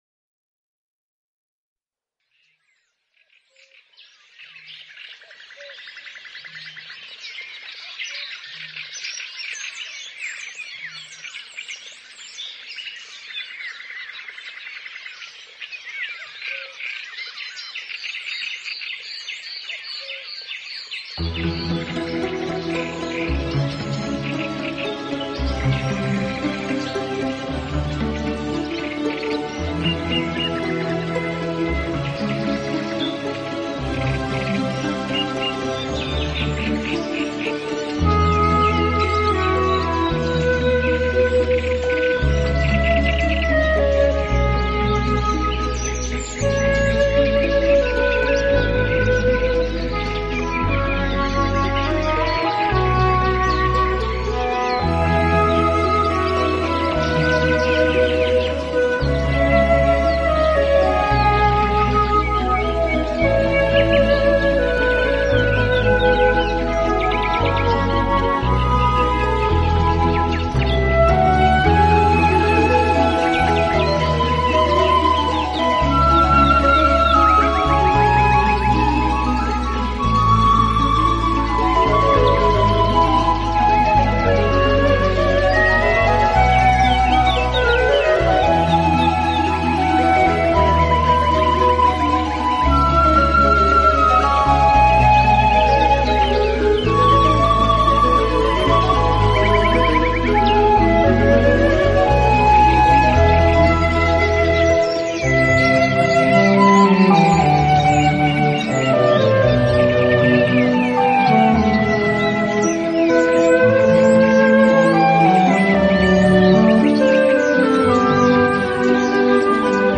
自然聲響與音樂的完美對話
流水、雀鳥之聲，能鎮靜人的情緒，鬆弛我們的身心，而且給人
海浪、流水、鳥鳴，風吹過樹葉，雨打在屋頂，
大自然的原始採樣加上改編的著名樂曲合成了天籟之音。